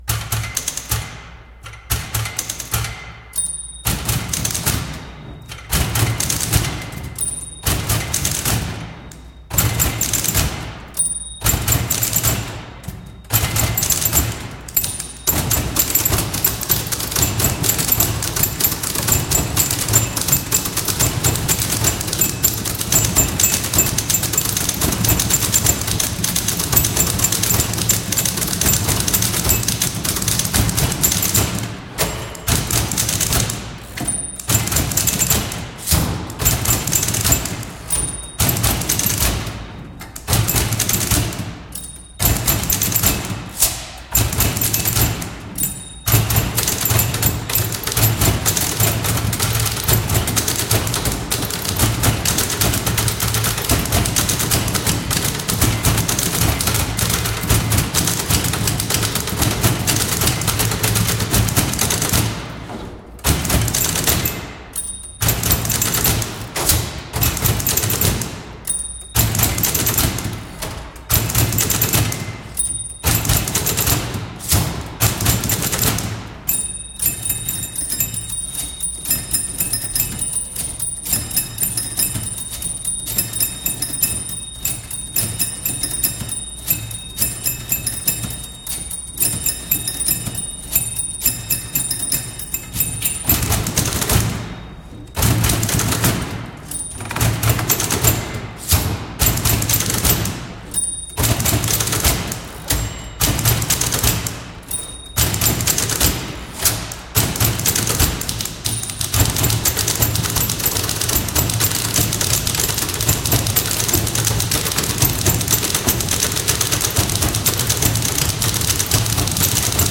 recording @ Zumix, East Boston. Mixed at The Home Office.